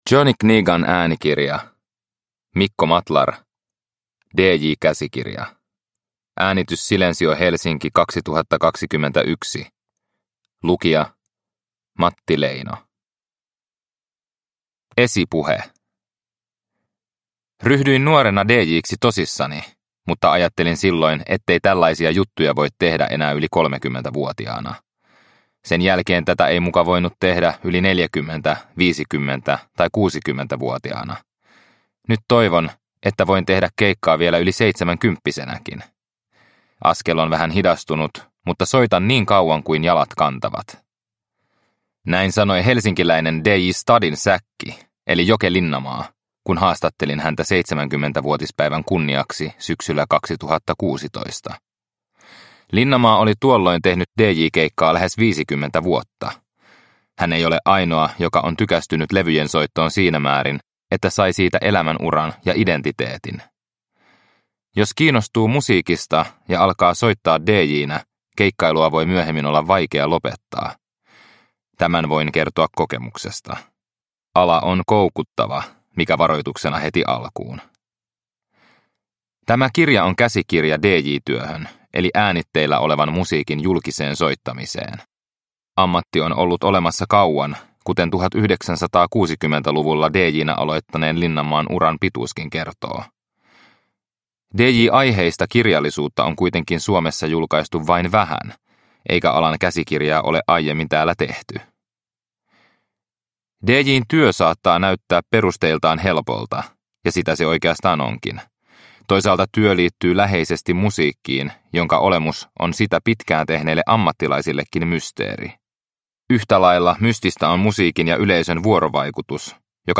Dj – Käsikirja – Ljudbok – Laddas ner